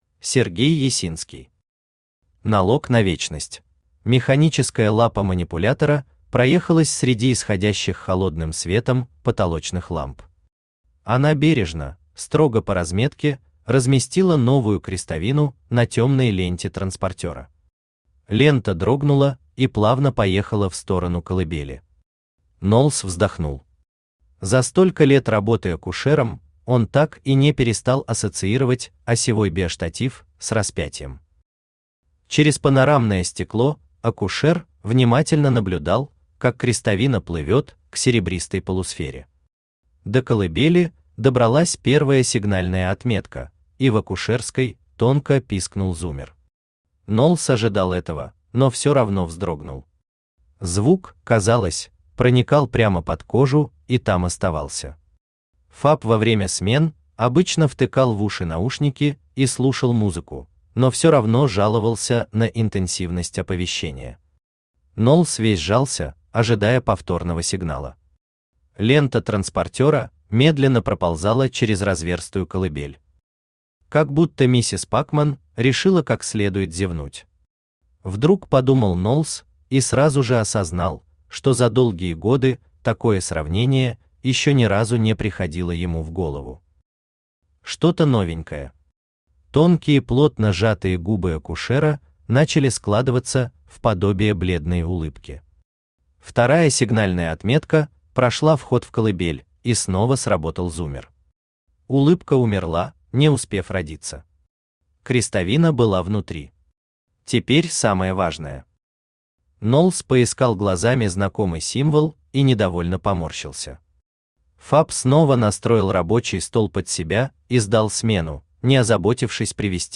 Аудиокнига Налог на вечность | Библиотека аудиокниг
Aудиокнига Налог на вечность Автор Сергей Леонидович Ясинский Читает аудиокнигу Авточтец ЛитРес.